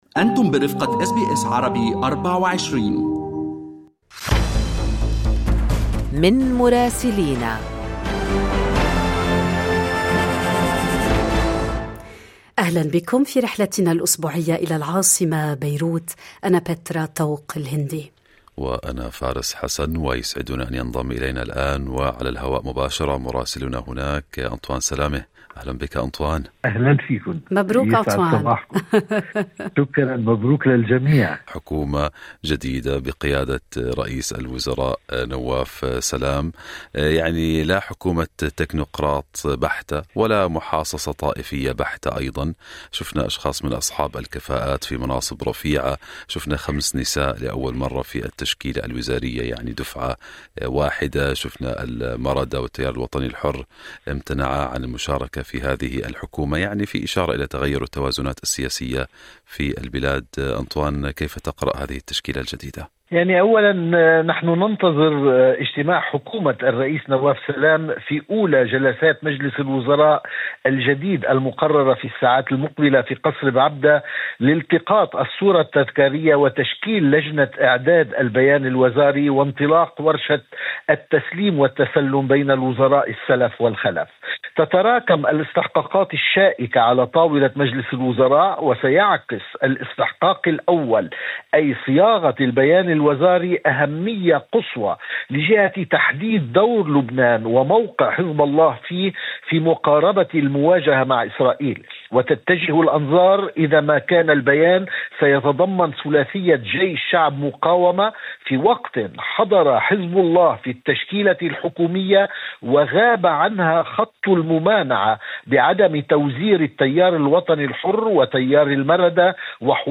من مراسلينا: أخبار لبنان في أسبوع 11/2/2025